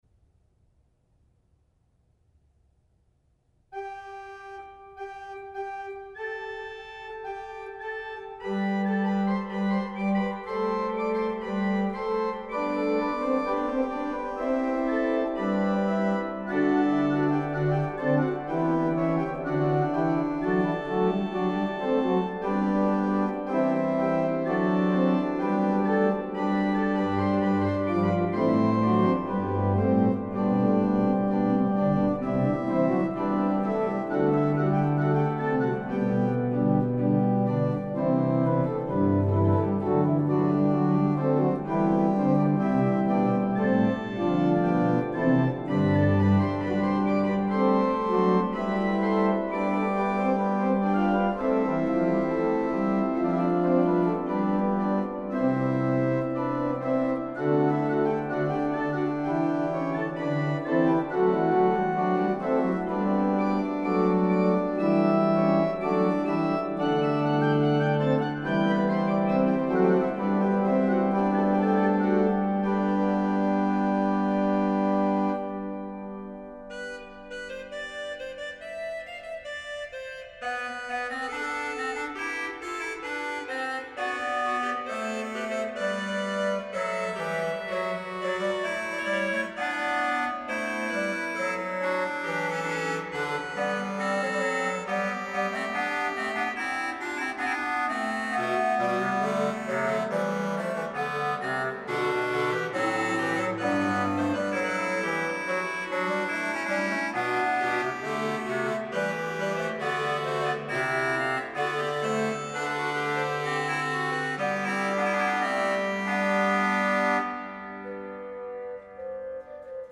It is an elaborate piece, in which each part of the melody gets an fugual treatment. The recording was done on the sample set of the Bader-organ in the Walburgiskerk in Zutphen by Sonus Paradisi for Hauptwerk. Registration Man: Prestant 8′, Octaaf 4′ Bar 19 RW: Quintadeen 8